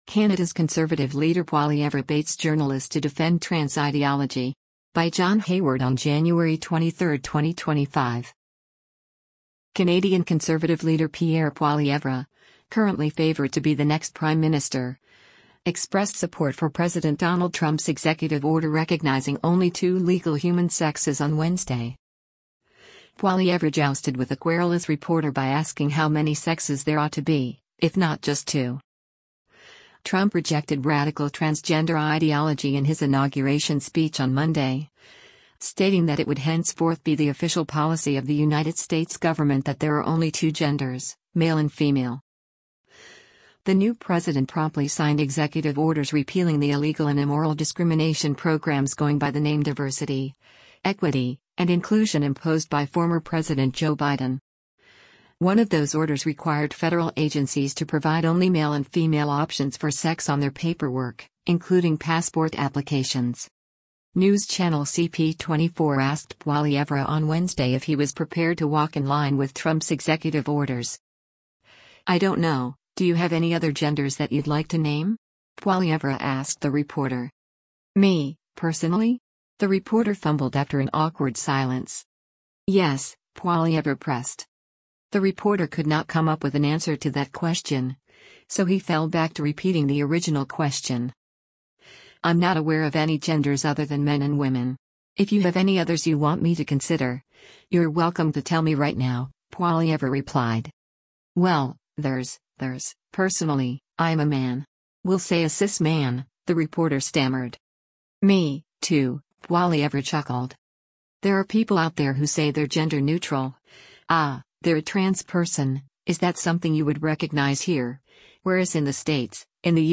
Canadian Conservative Party leader Pierre Poilievre speaks during a news conference on Par
“Me, too,” Poilievre chuckled.